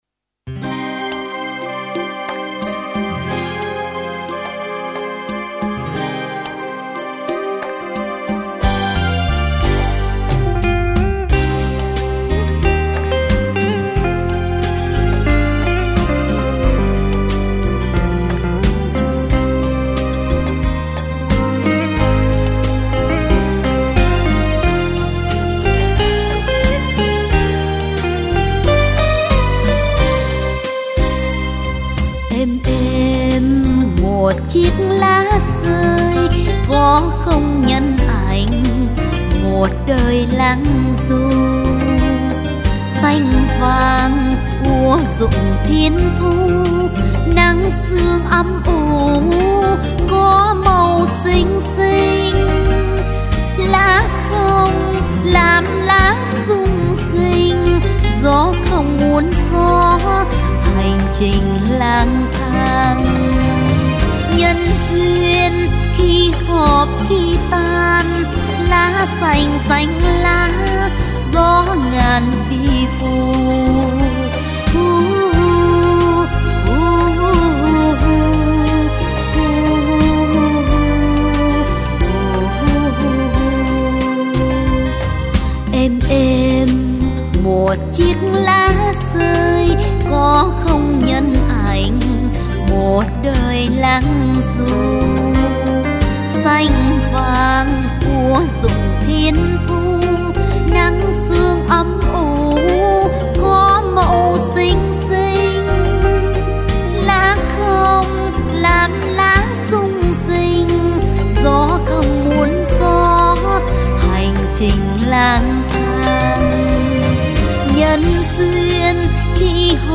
Category: Tân Nhạc